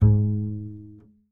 pluck
BS G#2 PI.wav